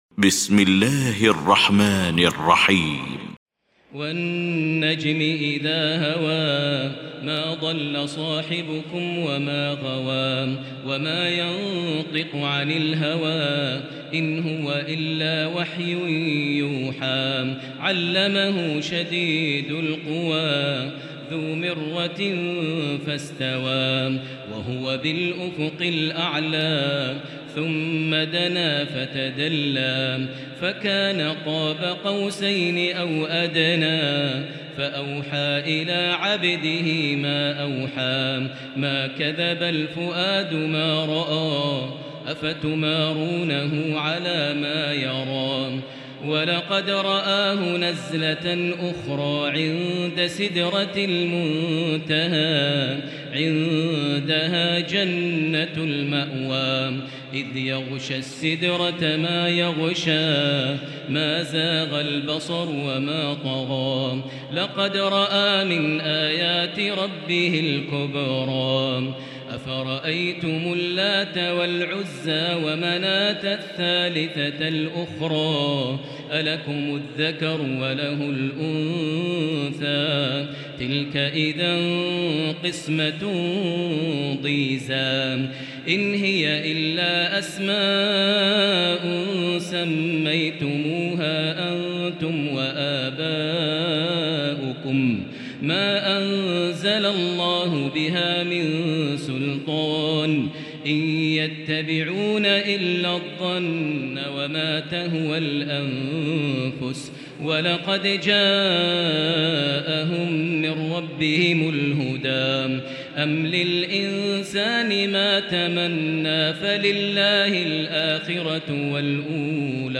المكان: المسجد الحرام الشيخ: فضيلة الشيخ ماهر المعيقلي فضيلة الشيخ ماهر المعيقلي النجم